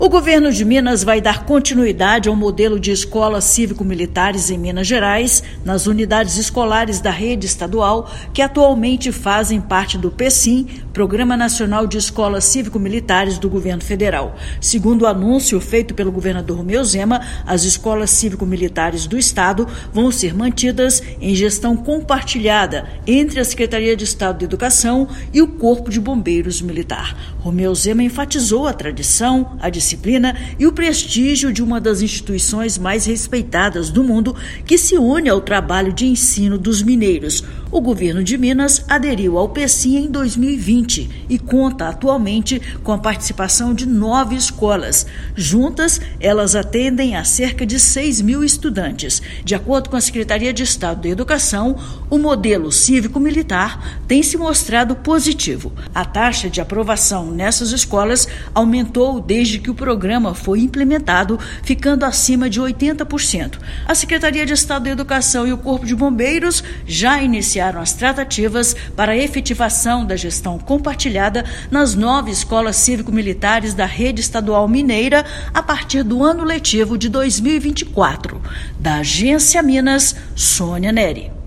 Medida foi anunciada pelo Governo de Minas. Ouça matéria de rádio.